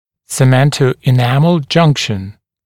[sɪˌmentəuɪ’næml ‘ʤʌŋkʃn][сиˌмэнтоуи’нэмл ‘джанкшн]эмалево-дентинная граница, цементно-эмалевое соединение